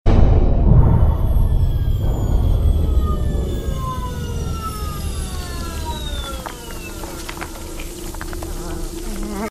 BIG ENGINE POWERING DOWN.mp3
Powering down a big generator.
big_engine_powering_down_gqg.ogg